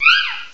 cry_not_purrloin.aif